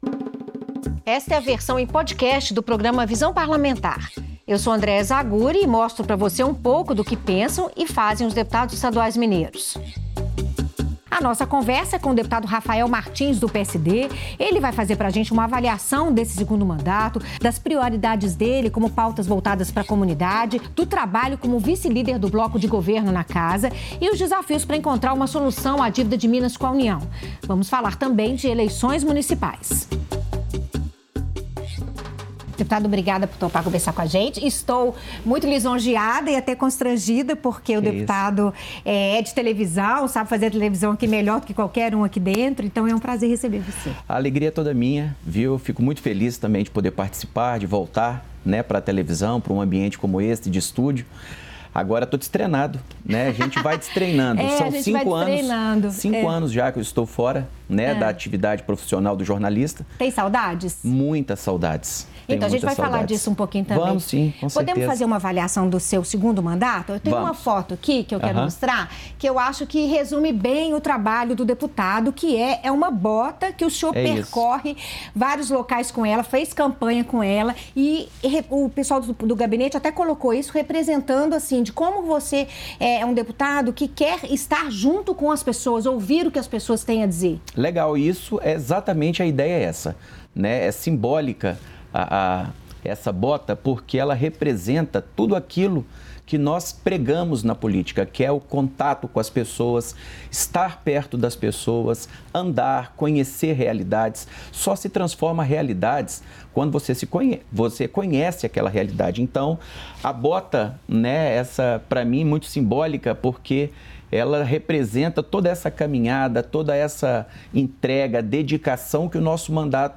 Em entrevista ao programa Visão Parlamentar, o deputado Rafael Martins (PSD) avalia a situação fiscal de Minas e afirma que o Estado terá que optar ou pelo Regime de Recuperação Fiscal (RRF), ou pelo Programa Pleno de Pagamento de Dívidas dos Estados (Propag), previsto no projeto de lei complementar apresentado pelo senador Rodrigo Pacheco (PSD-MG). Para Rafael Martins, a simples retomada do pagamento de parcelas da dívida não é uma opção viável, pois traria grandes dificuldades financeiras para o Estado, podendo levar ao atraso ou parcelamento do salário dos servidores. O parlamentar também fala sobre as disputas em torno da mineração na Serra do Curral, cartão-postal de Belo Horizonte.